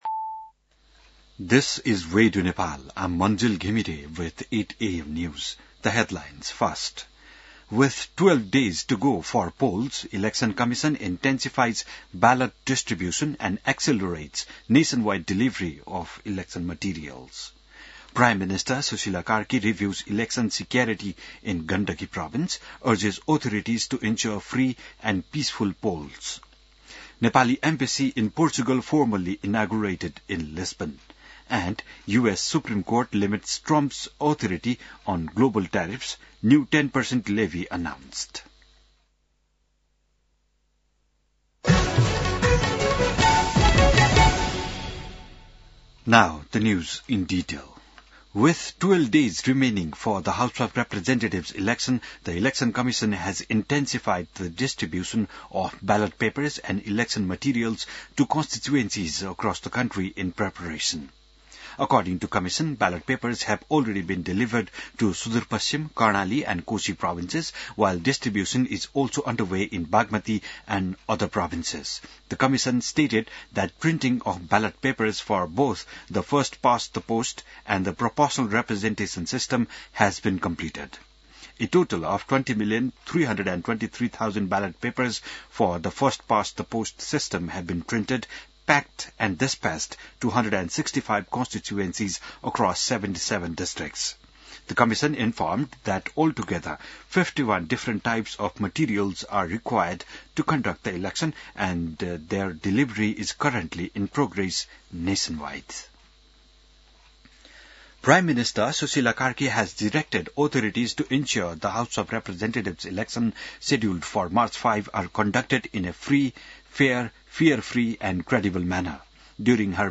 बिहान ८ बजेको अङ्ग्रेजी समाचार : ९ फागुन , २०८२